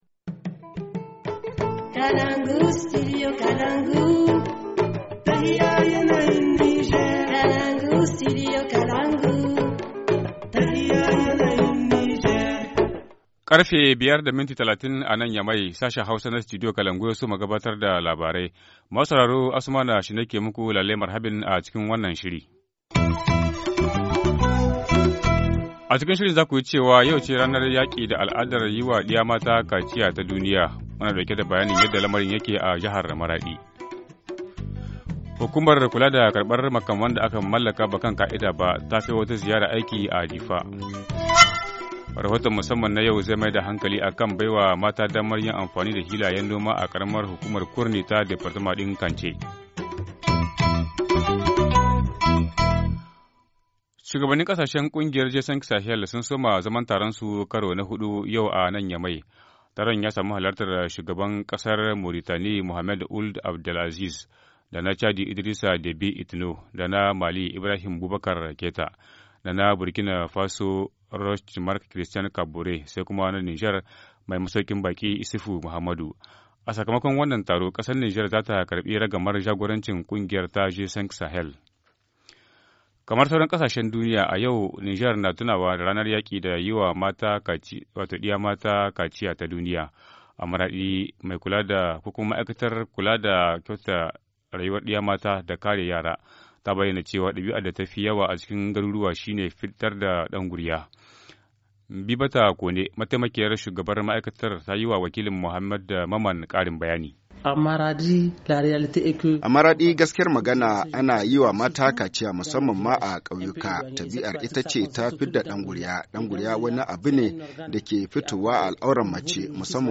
Journal du 6 février 2018 - Studio Kalangou - Au rythme du Niger